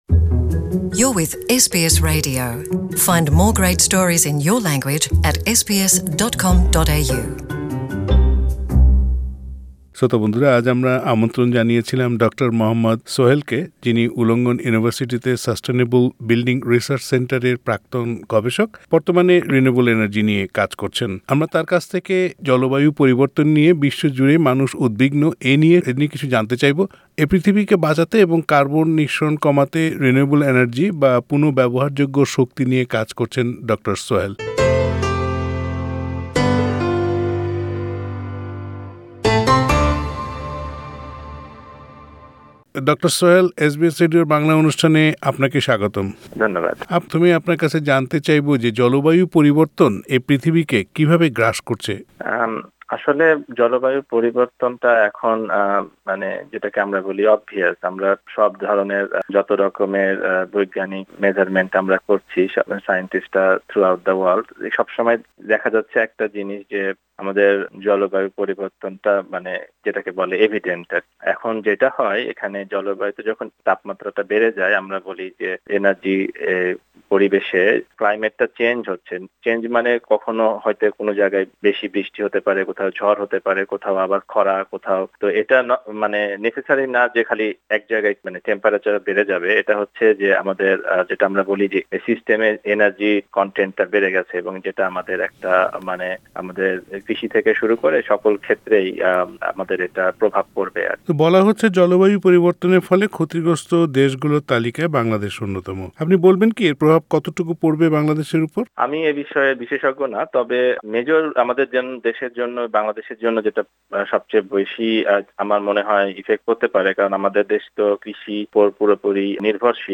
এসবিএস বাংলার সঙ্গে কথা বলেছেন তিনি।